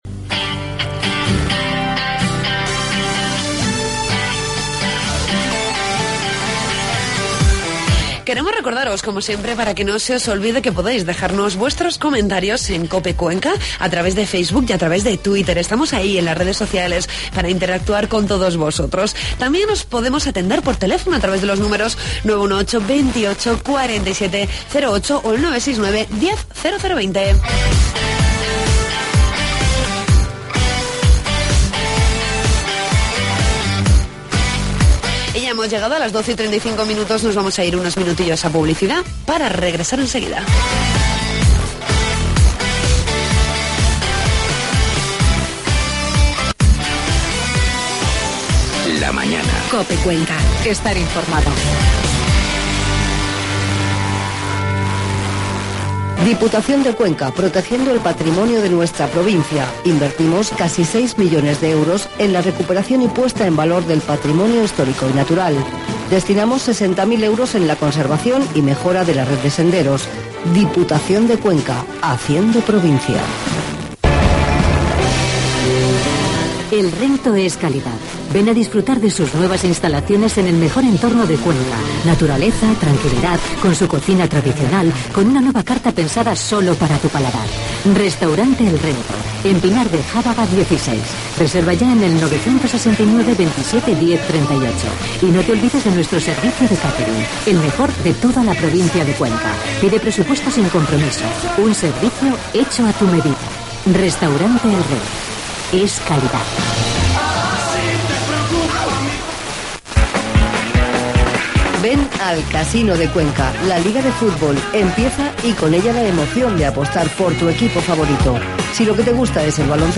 Entrevistamos al portavoz del Grupo Municipal Popular, Pedro García Hidalgo, con el tratamos diversos asuntos de actualidad municipal. Conocemos el cartel de las fiestas de San Mateo 2014 con la concejal de Festejos, Nieves Mohorte.